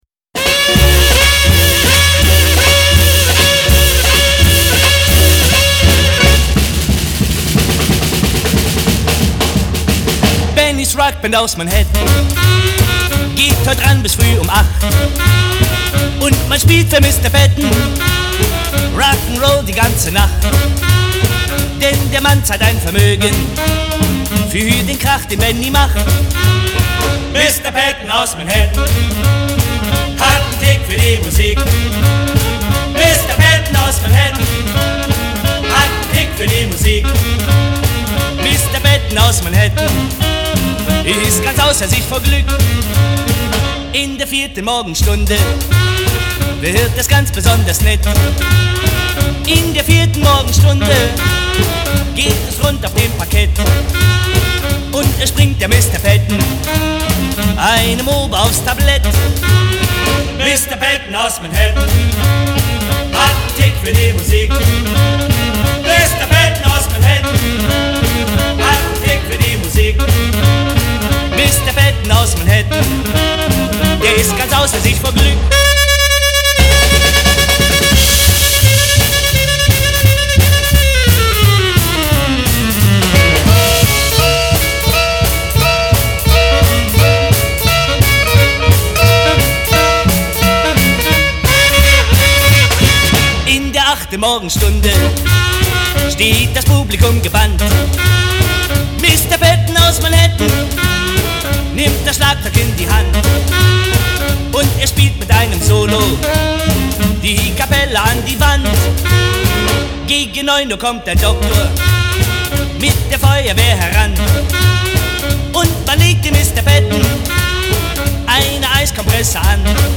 ГДР